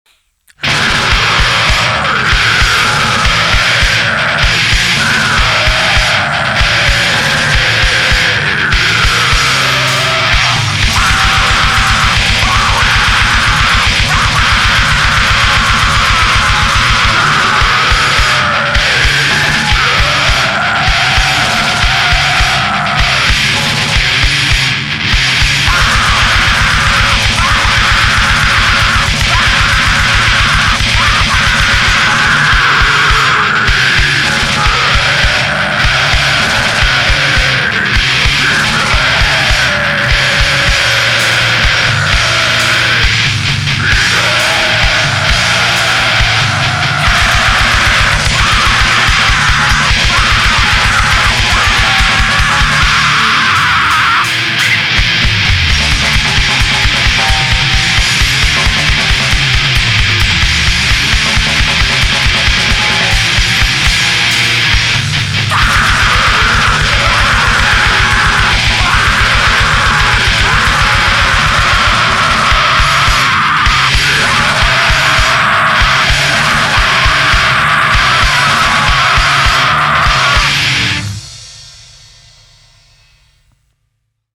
catchy yet extreme grind